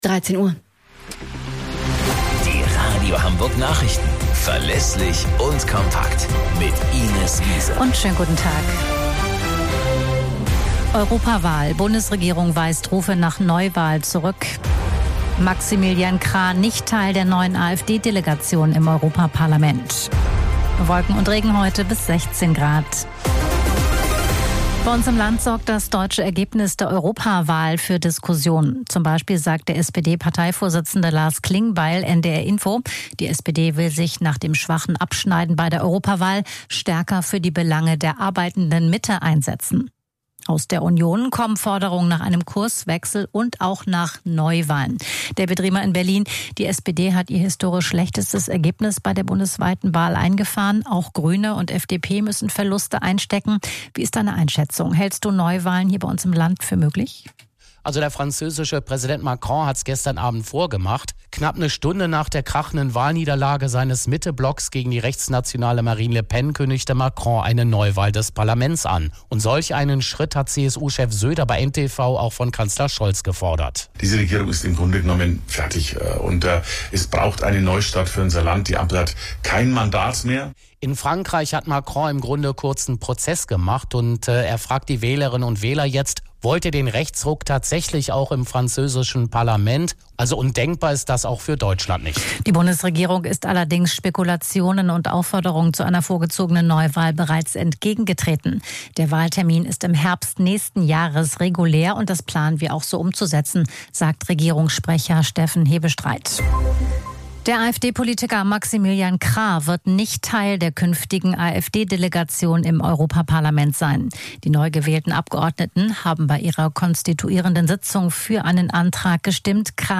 Radio Hamburg Nachrichten vom 10.06.2024 um 13 Uhr - 10.06.2024